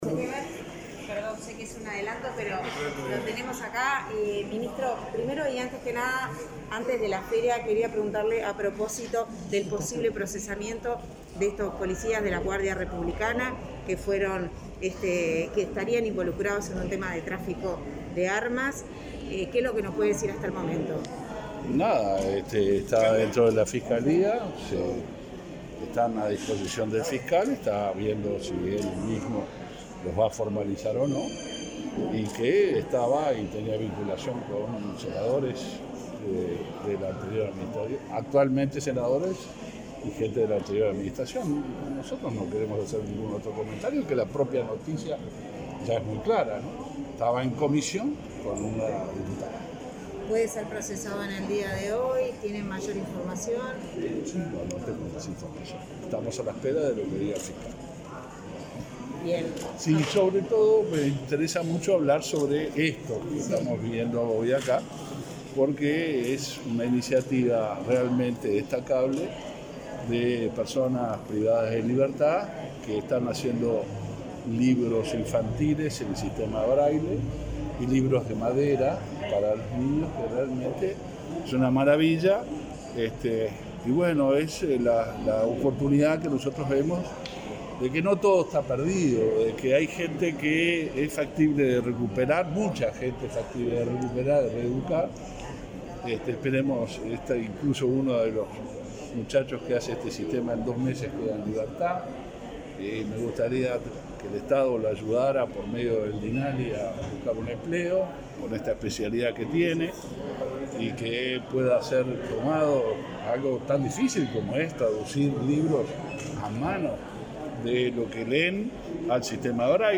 Declaraciones a la prensa del ministro del Interior, Luis Alberto Heber
El ministro del Interior, Luis Alberto Heber, visitó este jueves 4, el stand de la cartera en la 43.ª edición de la Feria Internacional del Libro de